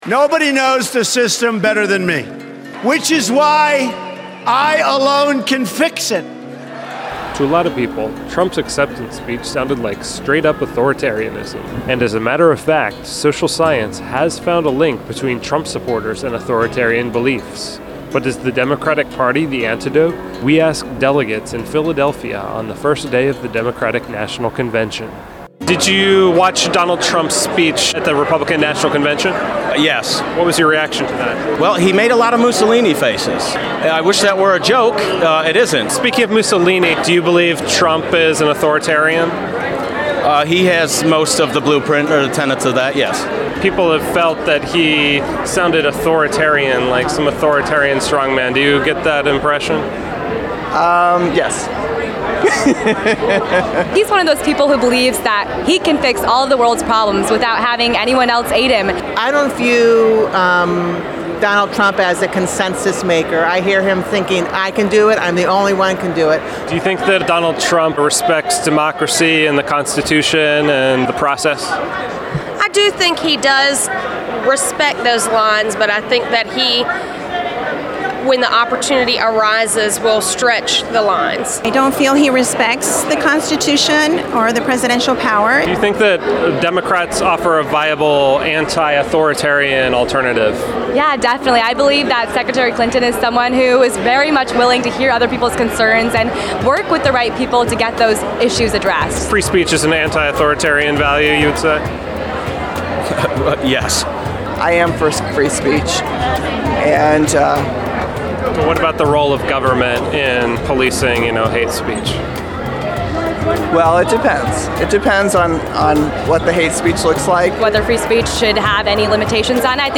Reason TV talked to delegates on day two of the Democratic National Convention in Philadelphia, PA and asked them how Clinton contrasted with Trump on major issues such as free speech, free trade, the sharing economy, gun rights, Citizens United, and foreign policy.